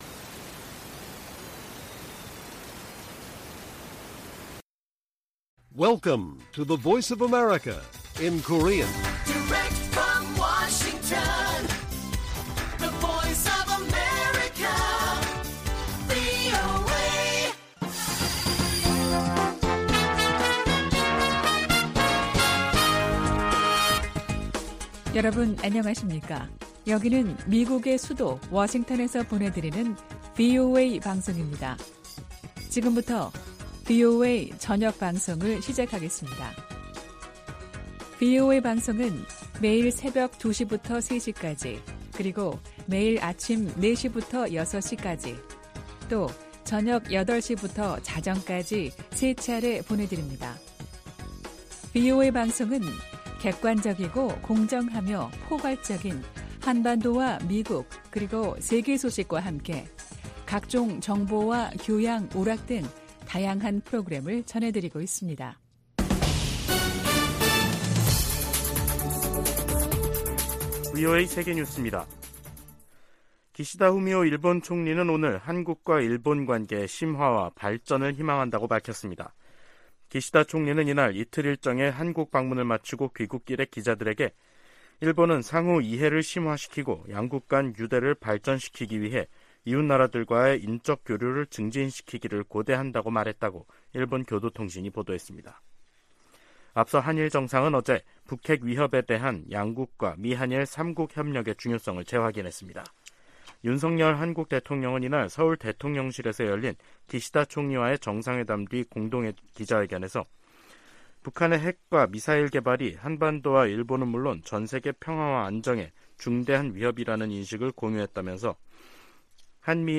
VOA 한국어 간판 뉴스 프로그램 '뉴스 투데이', 2023년 5월 8일 1부 방송입니다. 윤석열 한국 대통령과 기시다 후미오 일본 총리가 7일 북한이 제기하는 위협을 거론하며 미한일 3국 공조의 중요성을 강조했습니다.